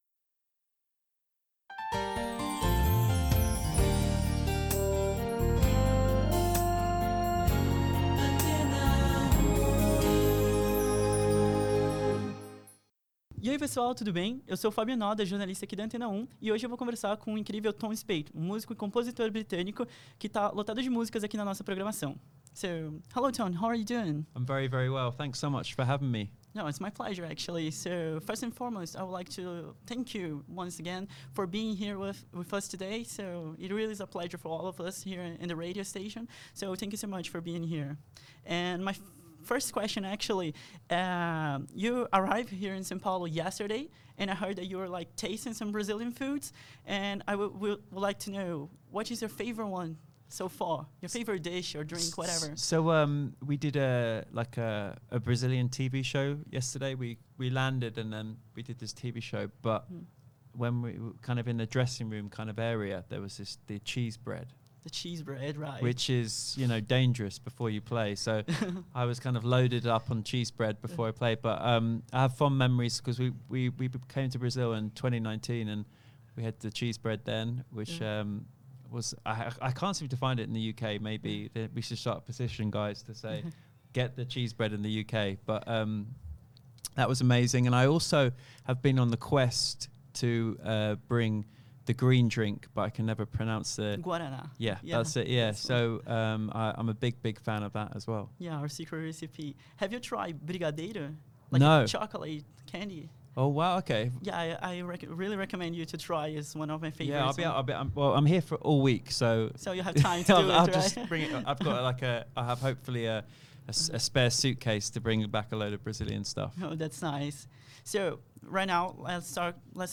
Intervista in Lingua inglese realizzata nei nostri studi di San Paolo in Brasile